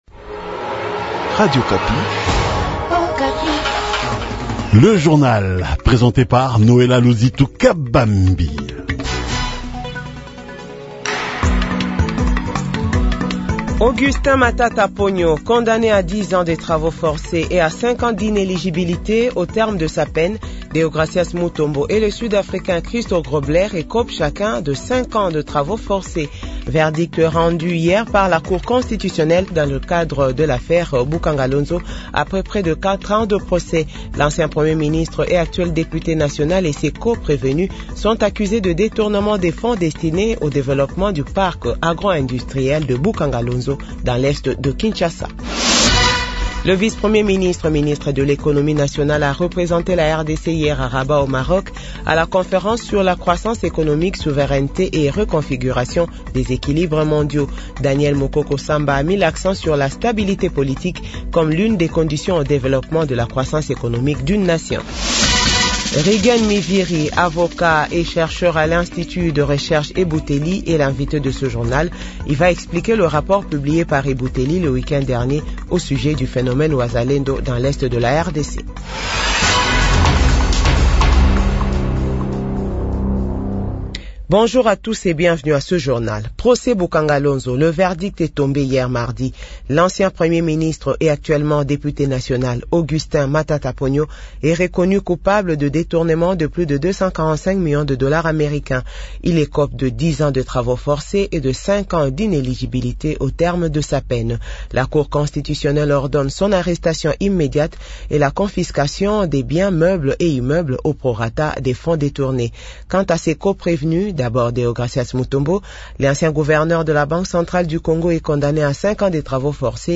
Journal 6h-7h